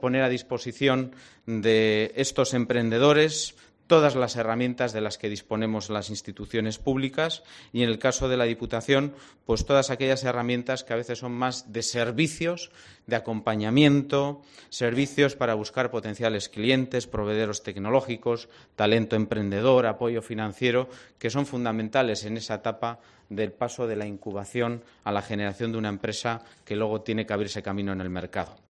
Imanol Pradales, Diputado de Desarrollo Económico